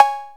808-Cowbell1.wav